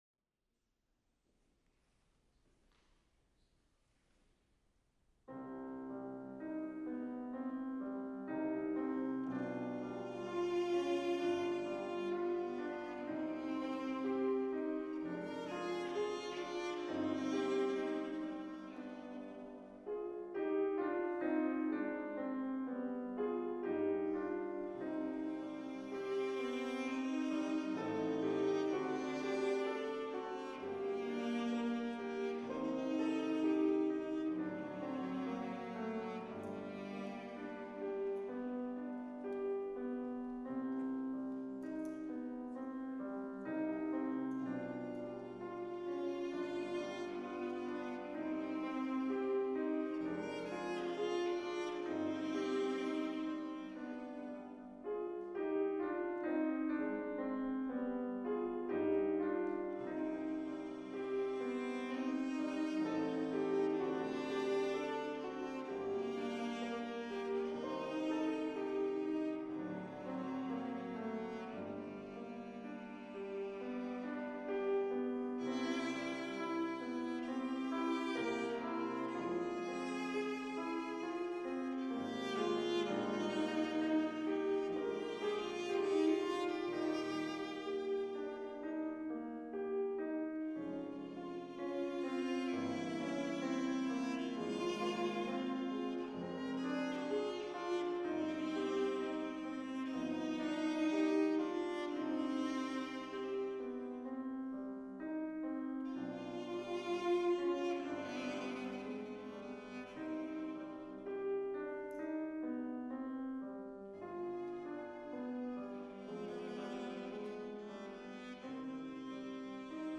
Selections from Recital in Shuck Recital Hall, Cape Girardeau, MO
cello
piano